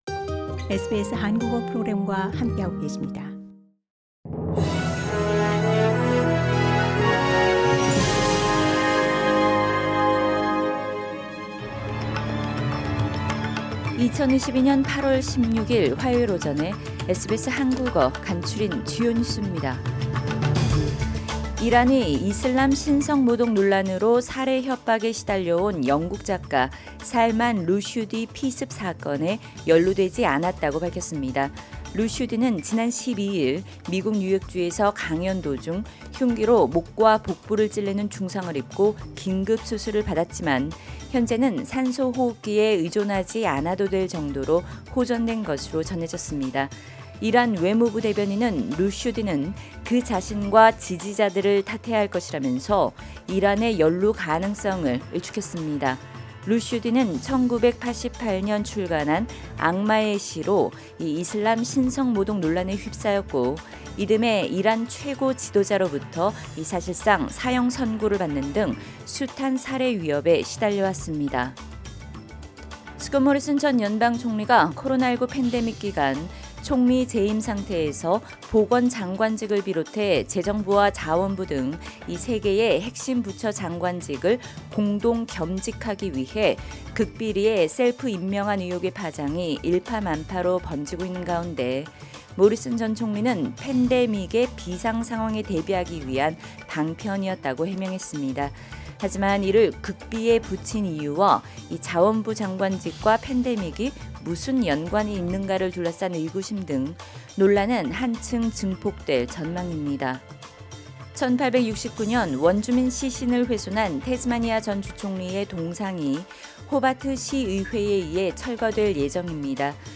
SBS 한국어 아침 뉴스: 2022년 8월 16일 화요일